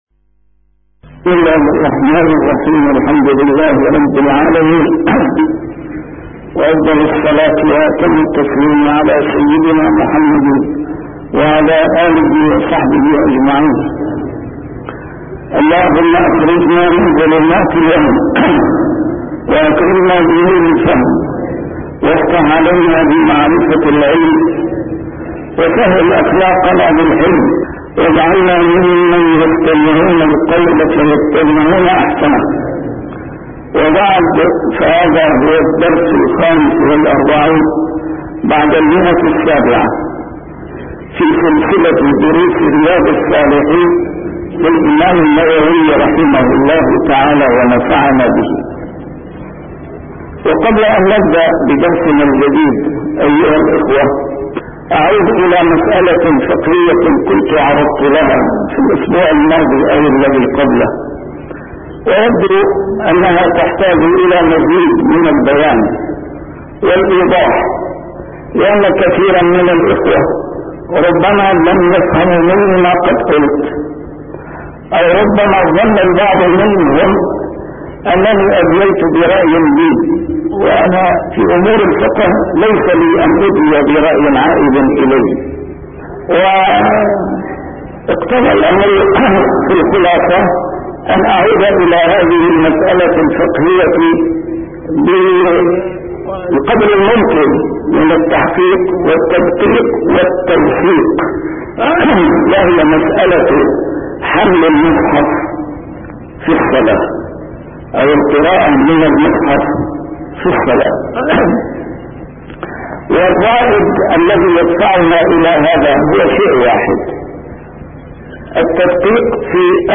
A MARTYR SCHOLAR: IMAM MUHAMMAD SAEED RAMADAN AL-BOUTI - الدروس العلمية - شرح كتاب رياض الصالحين - 745- شرح رياض الصالحين: فضل قراءة القرآن